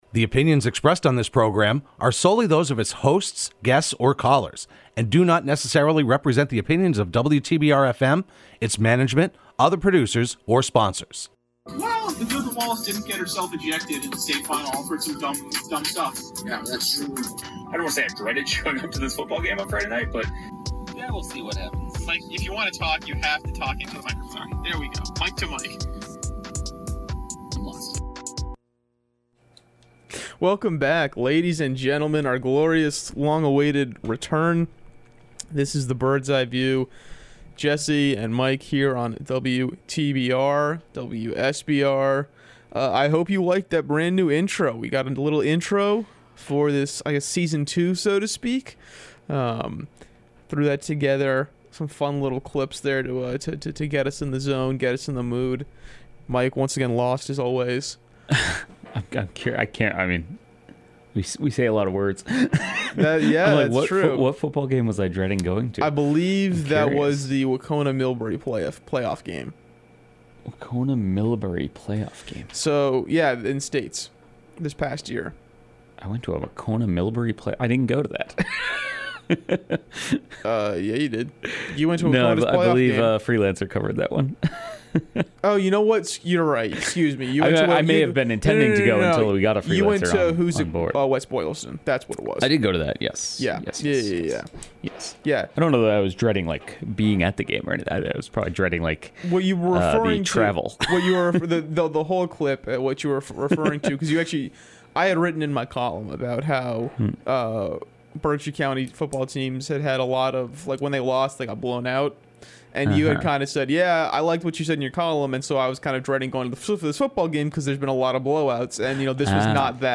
They can be heard live every Thursday morning at 10am on WTBR.